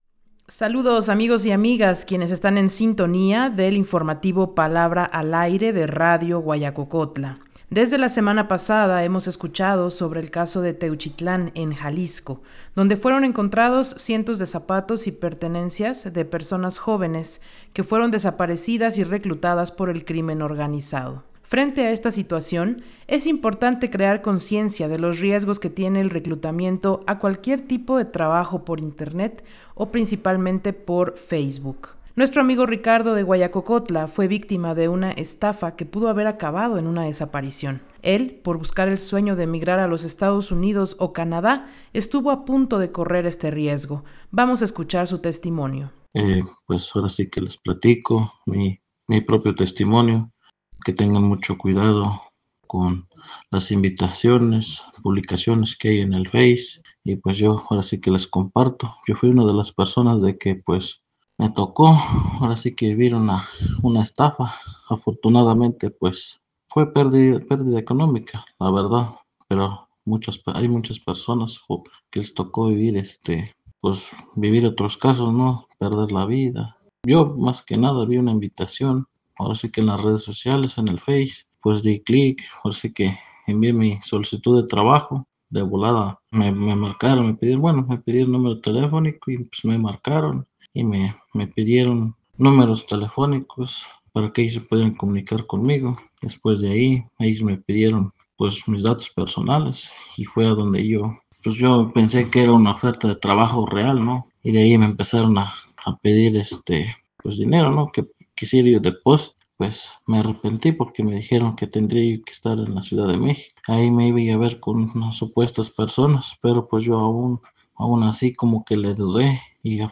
el reporte